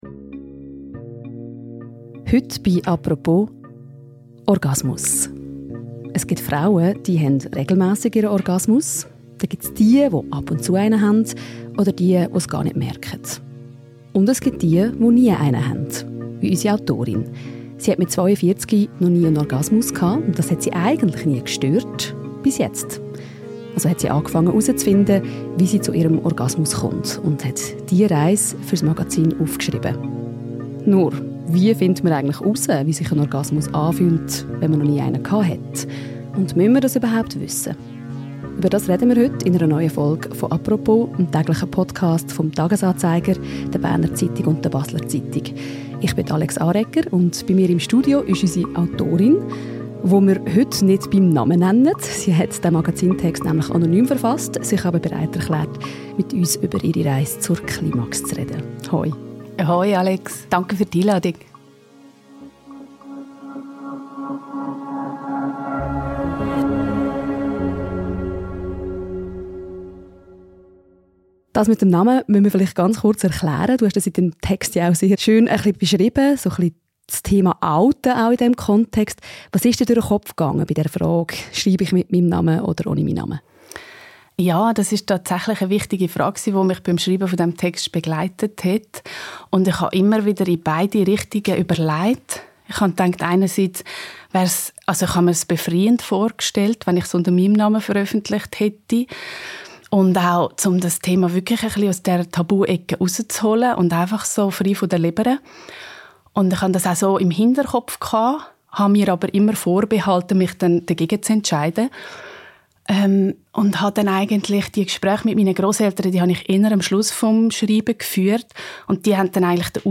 Ins Studio des täglichen Podcasts «Apropos» kam sie trotzdem – und spricht über ihre Erkenntnisse aus dieser Recherche.